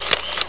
camera.mp3